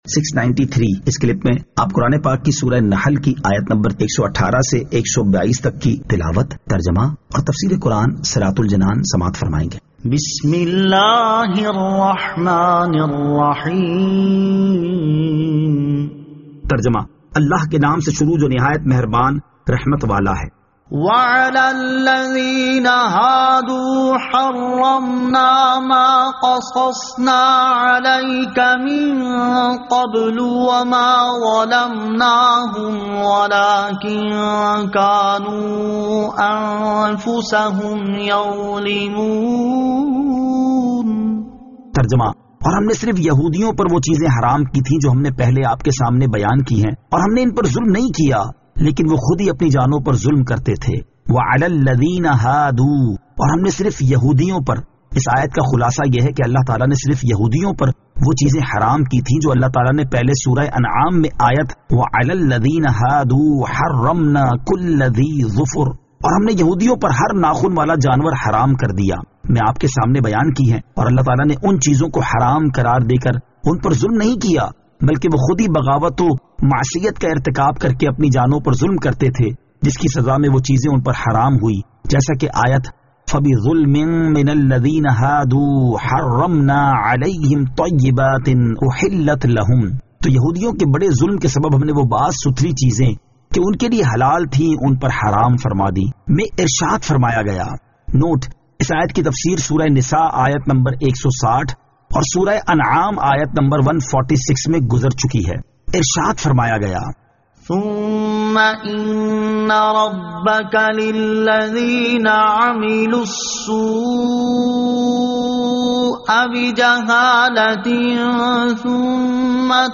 Surah An-Nahl Ayat 118 To 122 Tilawat , Tarjama , Tafseer